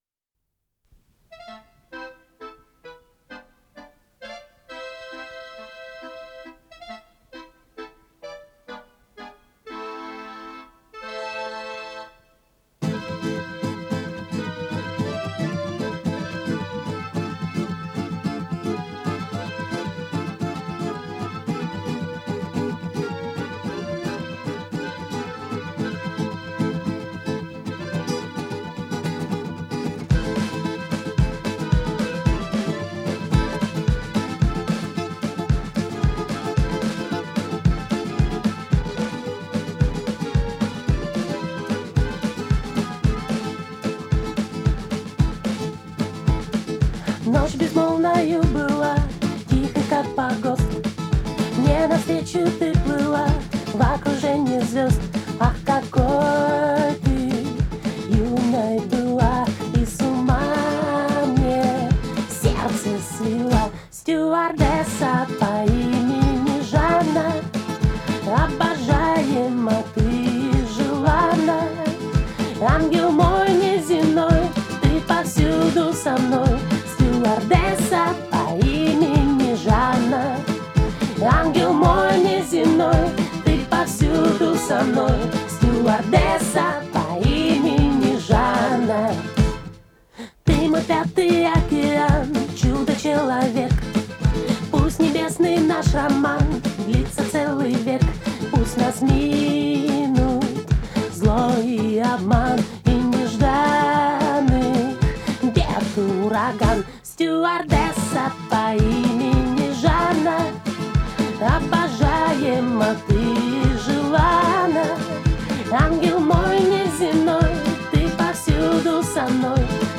с профессиональной магнитной ленты
АккомпаниментАнсамбль п/у автора
Скорость ленты38 см/с
МагнитофонМЭЗ-109М